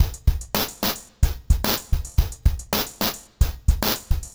RemixedDrums_110BPM_40.wav